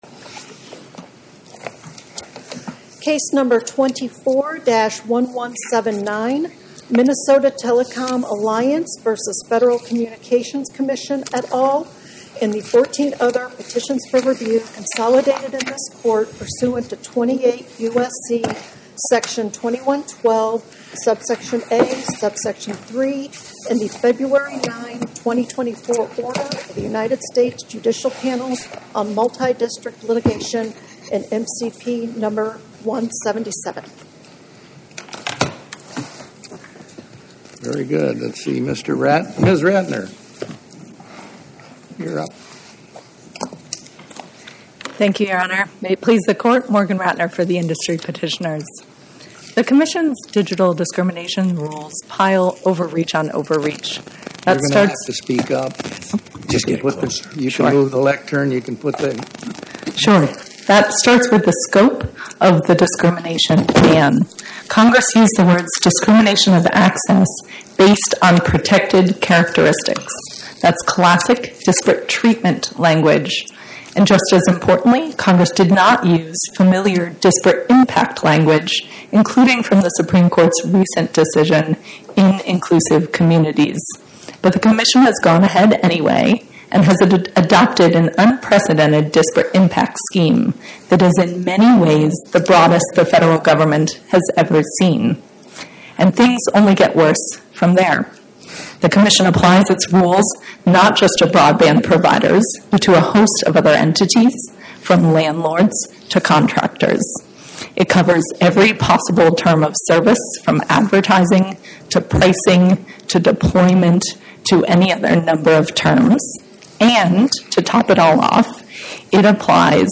St. LOUIS, MO — Lawyers’ Committee for Civil Rights Under Law and the FCC argued Wednesday before the Eighth Circuit Court of Appeals in defense of new rules prohibiting discrimination by broadband providers. The rules, issued by the Federal Communications Commission, would facilitate equal access to broadband and prohibit disparate impacts on the basis of race and income.